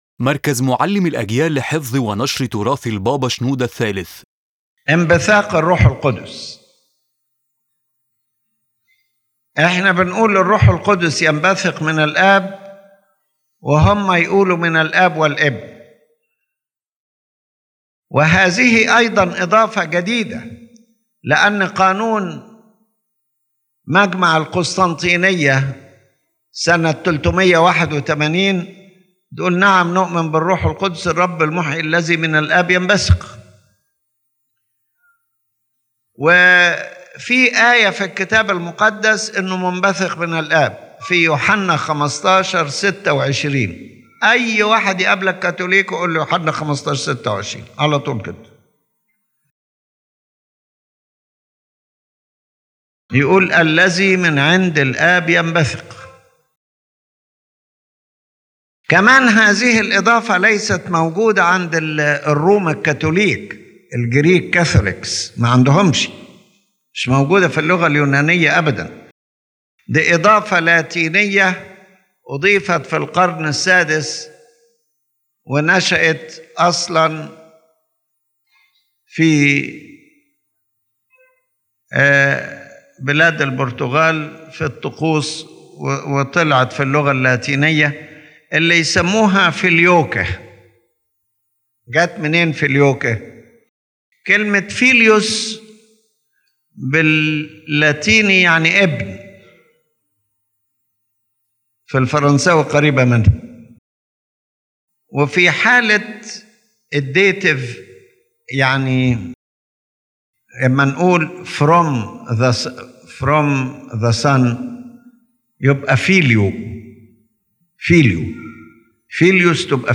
In this lecture, Pope Shenouda III explains the Orthodox teaching about the procession of the Holy Spirit, highlighting the difference between the Coptic Orthodox and Catholic understanding, and then discusses the concept of physical separation in marriage within Catholic thought and the Orthodox view on divorce.